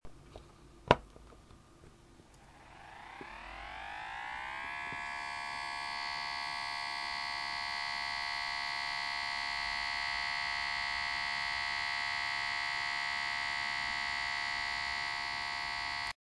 iPod 1G Starting Up...uh oh!